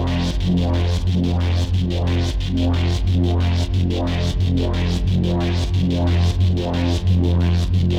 Index of /musicradar/dystopian-drone-samples/Tempo Loops/90bpm
DD_TempoDroneE_90-F.wav